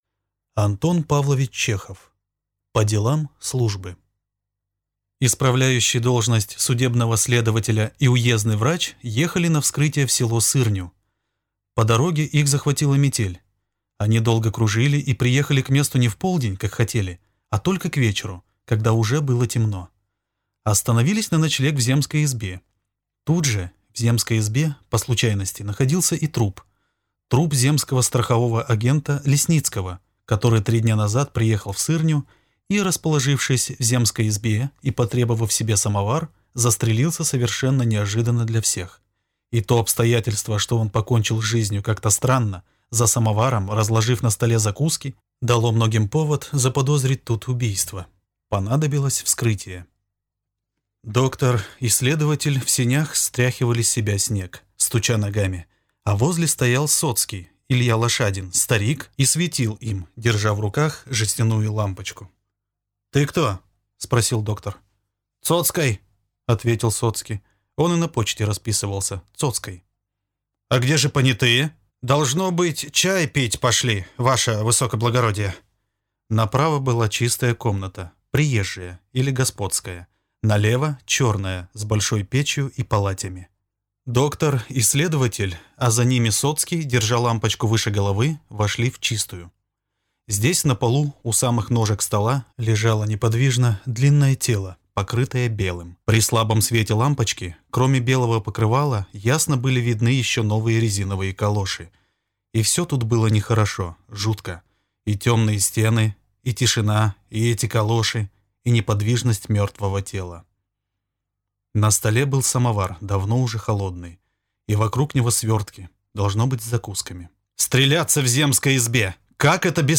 Аудиокнига По делам службы | Библиотека аудиокниг